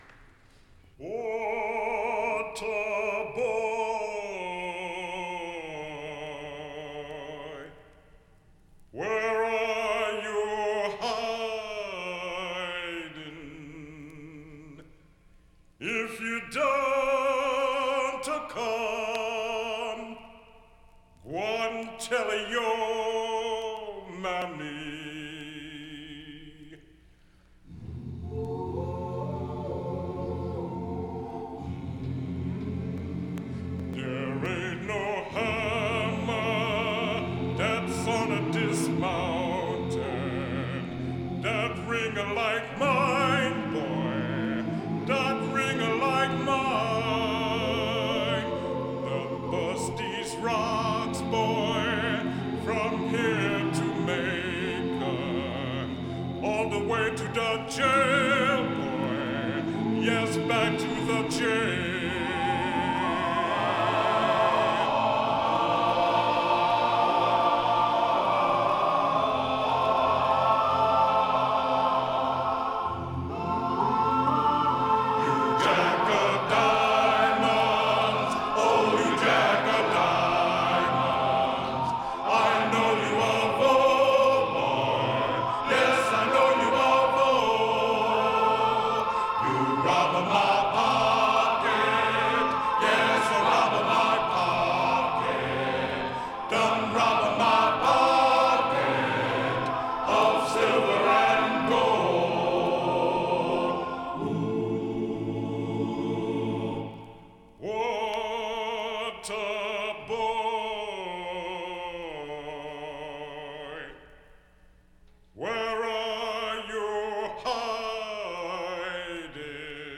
Here are a couple of songs featuring him as the soloist.